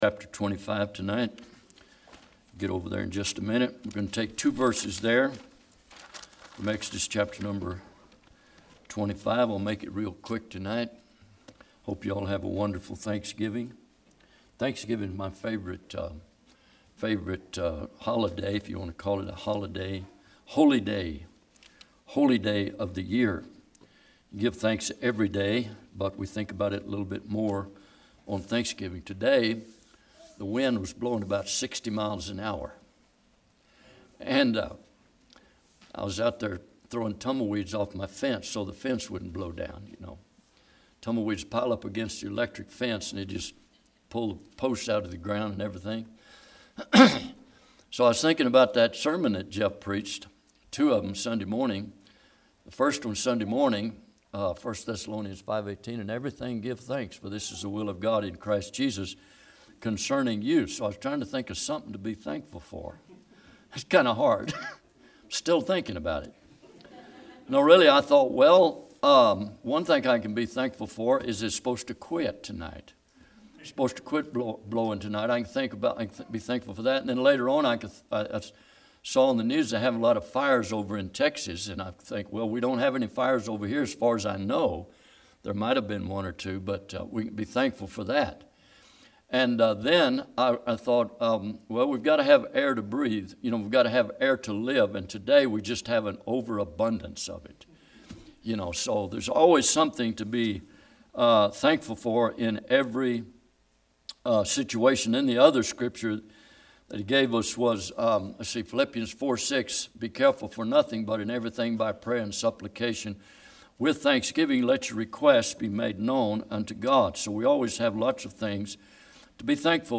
Exodus 25:8-9 Service Type: Midweek Bible Text